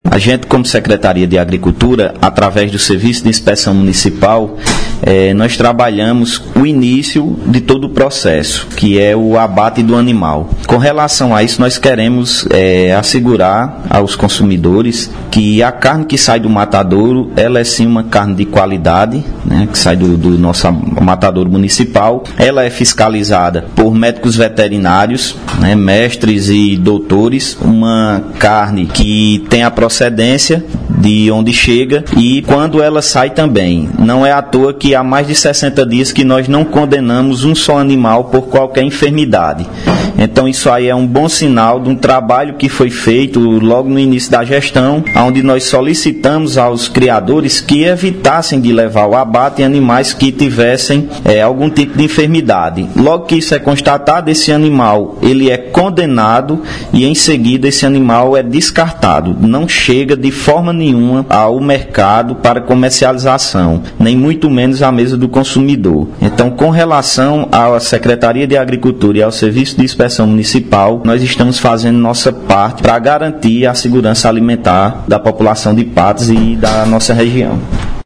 Secretário de Agricultura, João Paulo de Lacerda –